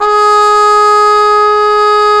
Index of /90_sSampleCDs/Roland L-CD702/VOL-2/BRS_Cup Mute Tpt/BRS_Cup Mute Dry